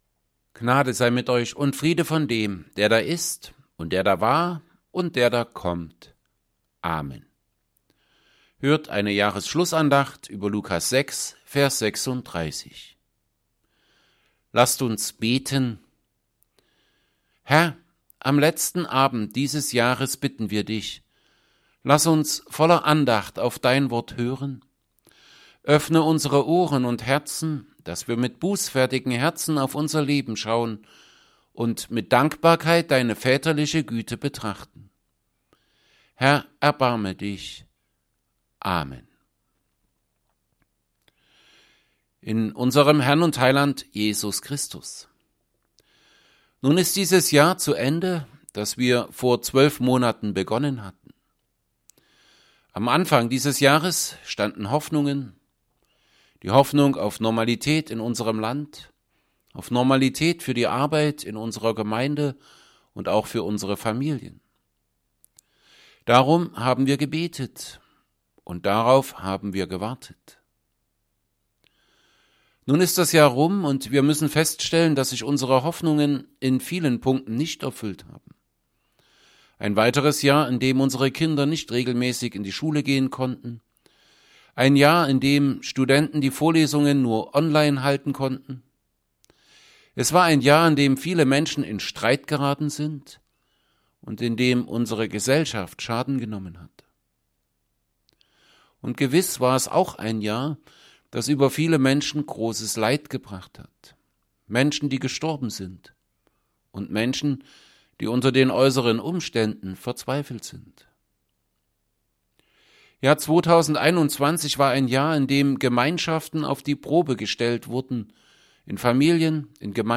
Evangelienpredigten
Jahresschlussandacht_2022.mp3